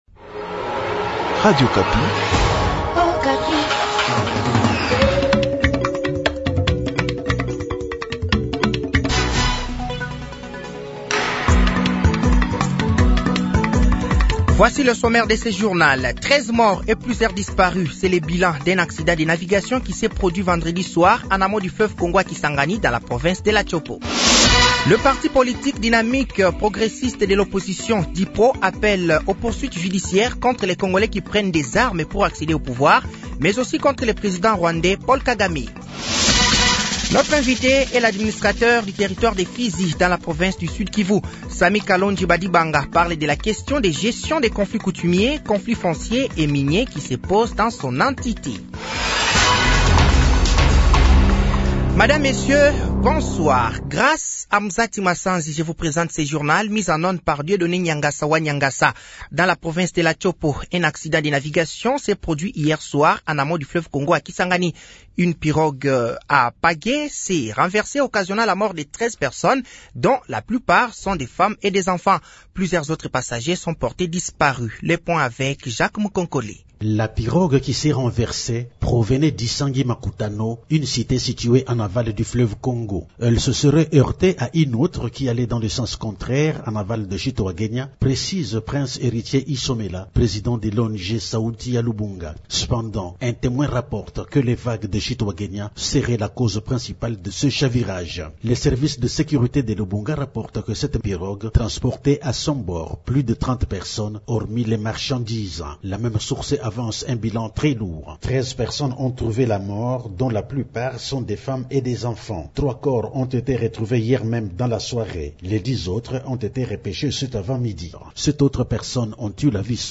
Journal français de 18h de ce samedi 02 mars 2024